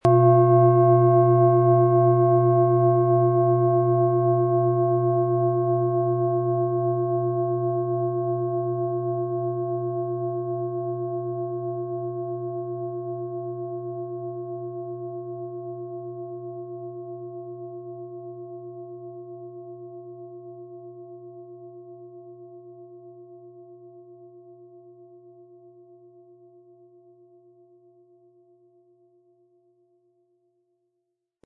Sonne & Wasser-Ton Klangschale Ø 15,9 cm im Sound-Spirit Shop | Seit 1993
• Mittlerer Ton: Wasser
Um den Original-Klang genau dieser Schale zu hören, lassen Sie bitte den hinterlegten Sound abspielen.
Der Klöppel lässt die Klangschale voll und harmonisch tönen.
HerstellungIn Handarbeit getrieben
MaterialBronze